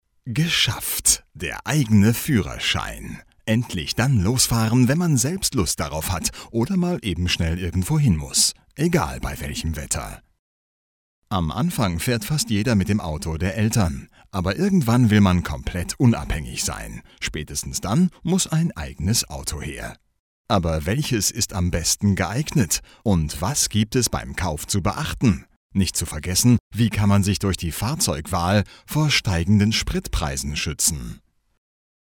deutscher Sprecher, Stationvoice für 2 deutsche Radios, hunderte zufriedene, deutsche und internationale Kunden: Werbung, Industriefilme, e-learning, VO talent
Kein Dialekt
Sprechprobe: Werbung (Muttersprache):